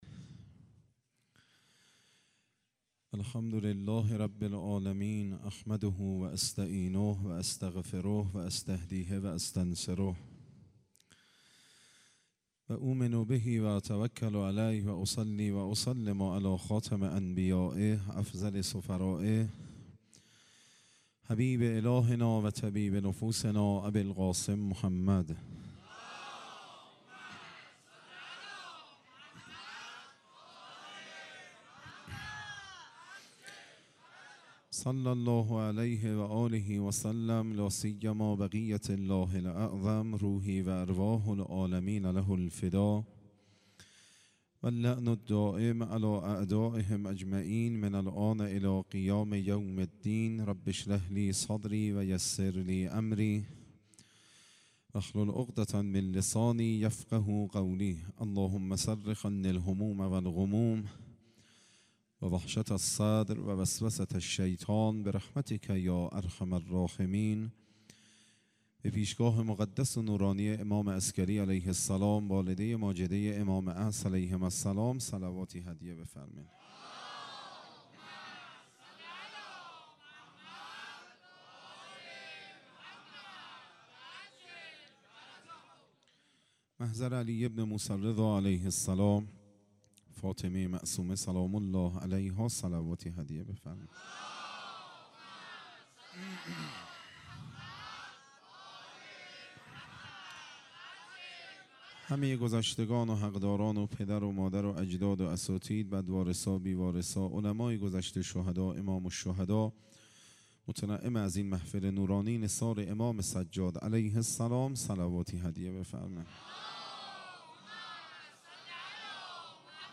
سخنرانی شهادت امام سجاد علیه السلام